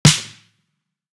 Snares
Low Sizzle.wav